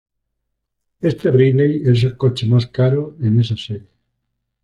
Pronounced as (IPA) /ˈkaɾo/